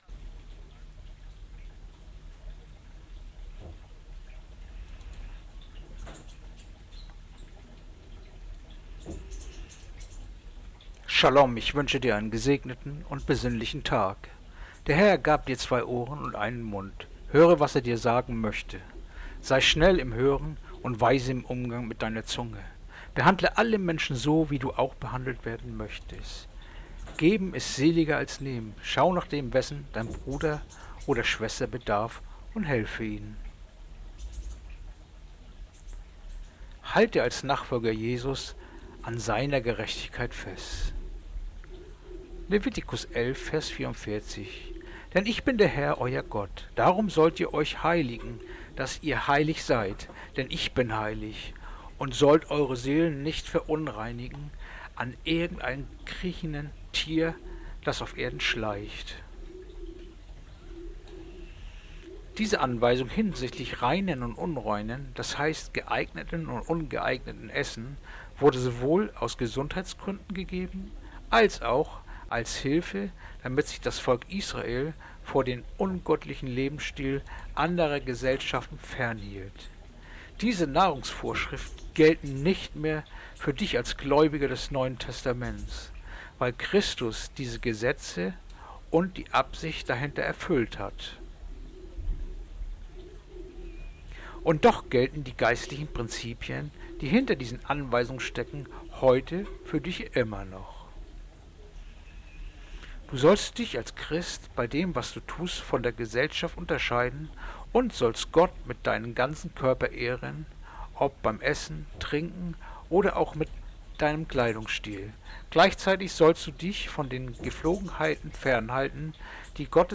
Andacht-vom-10.-September-Leviticus-11-44